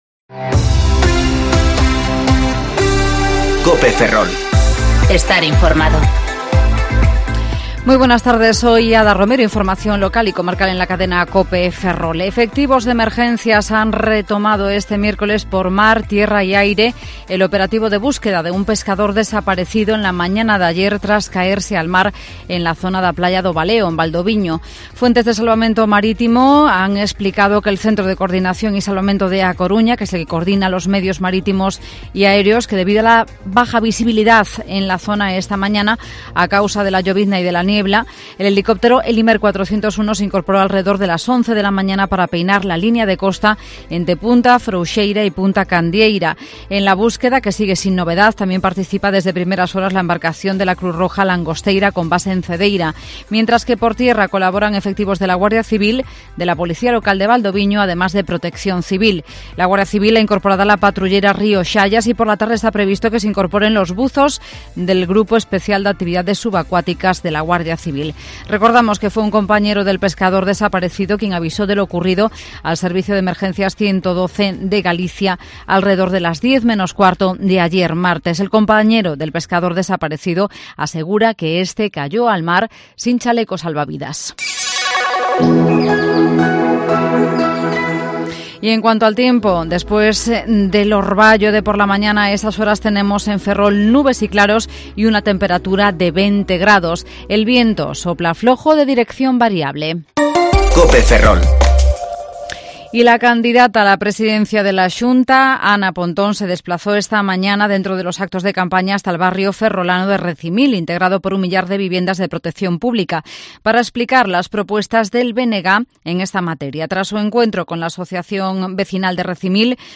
Informativo Mediodía COPE Ferrol 1/07/2020 ( De 14.20 a 14.30 horas)